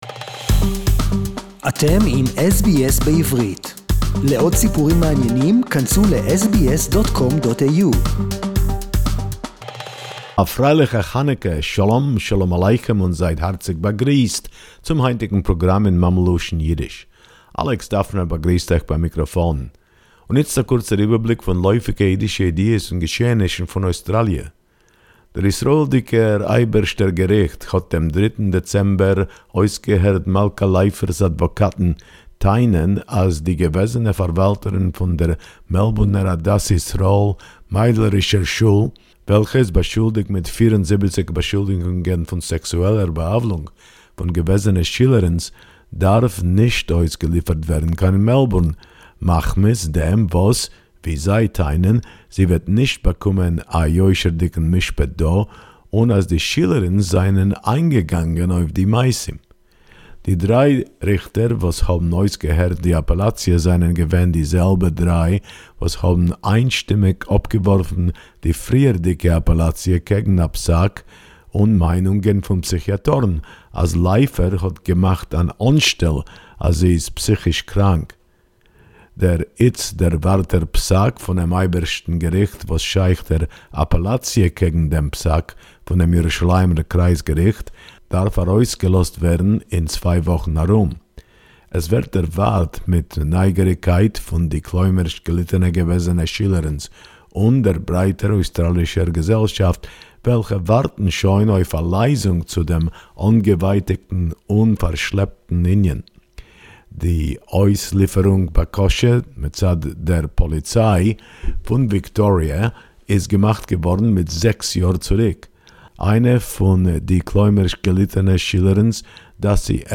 Yiddish report